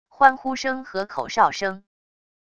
欢呼声和口哨声wav音频